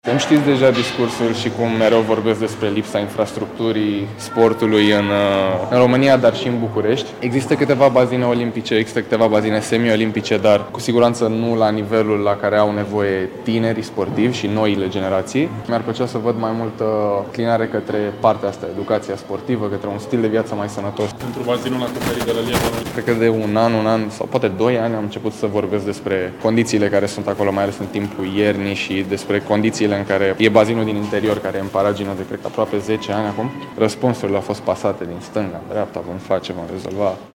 Într-o situație similară se află și ceilalți sportivi români, a precizat dublul campion mondial și olimpic la înot în fața jurnaliștilor la gala organizată de către Agenția Națională pentru Sport.